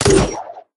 gr_launch02.ogg